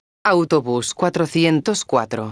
megafonias exteriores
autobus_404.wav